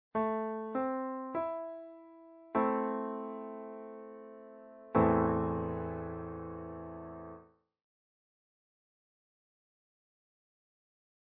In the above example we see the evolution from an A minor arpeggio pitches stacked into an A minor triad. The chord in bar three is the essential open A minor chord.